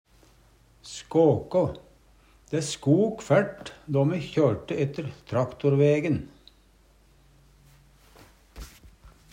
DIALEKTORD PÅ NORMERT NORSK skåkå skake, riste Infinitiv Presens Preteritum Perfektum skåkå sjek skok skakje Eksempel på bruk Dæ skok fært då me kjørte ette traktorvegen.